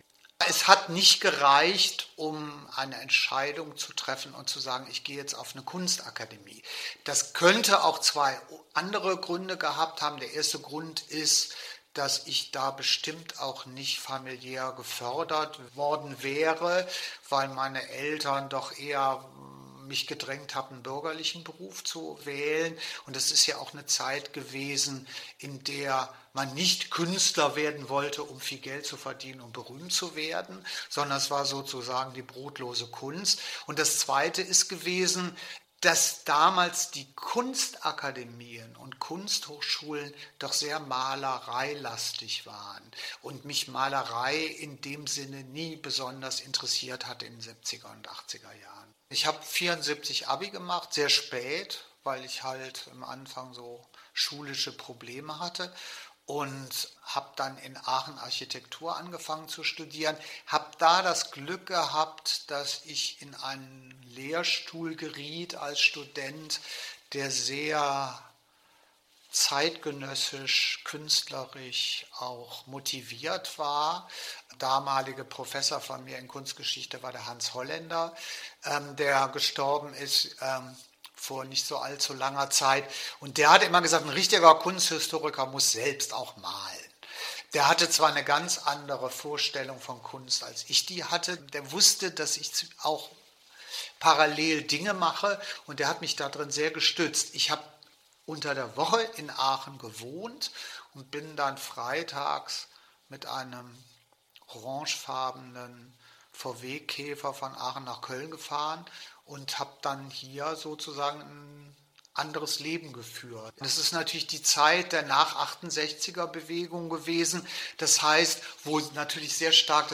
Interview Audioarchiv Kunst: Marcel Odenbach über das (Selbst)studium der Videokunst